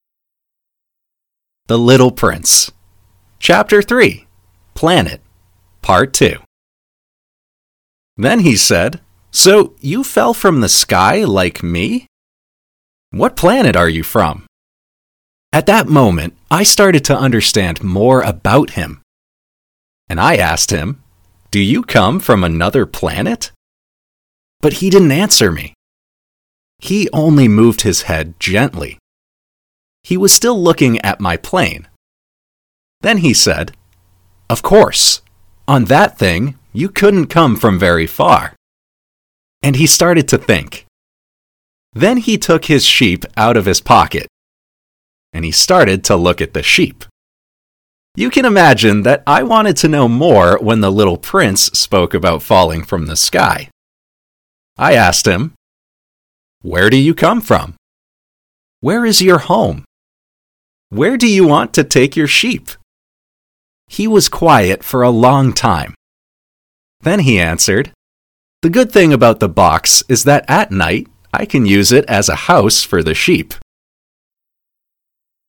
native speakers